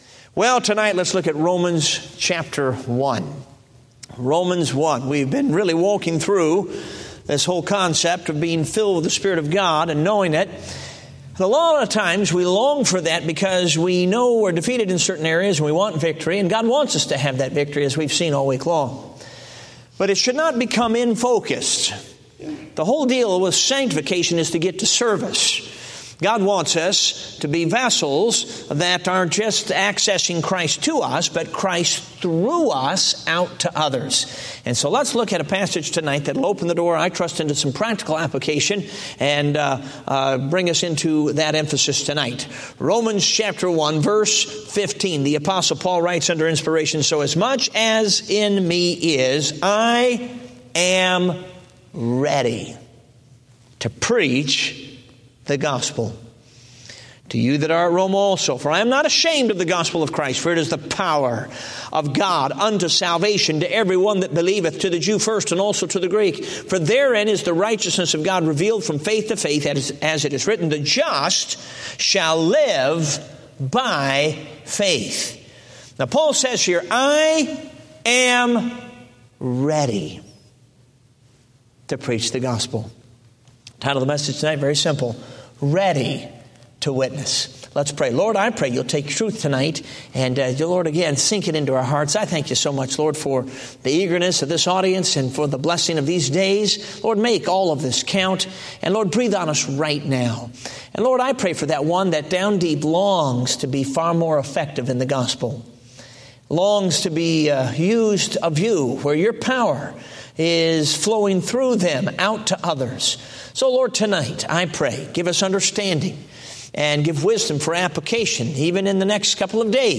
Date: September 10, 2015 (Revival Meeting)